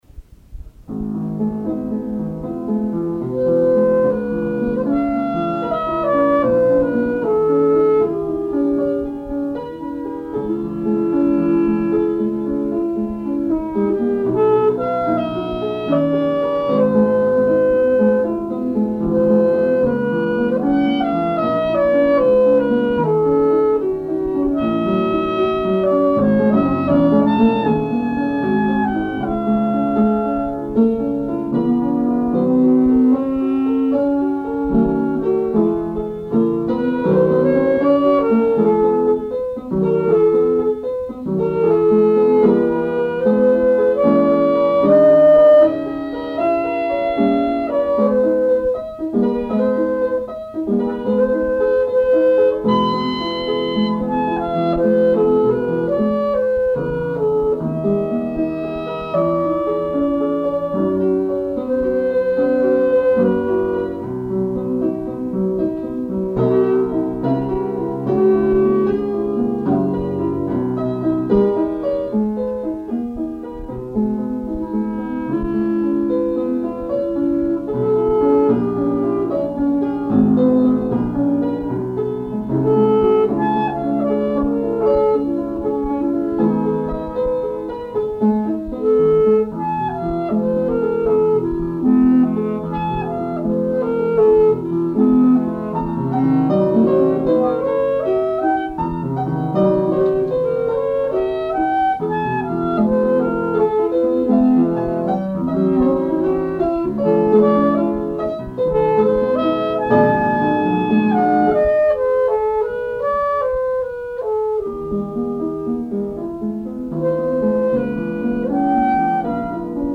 Fantasy Piece for Clarinet and Piano
clarinet
piano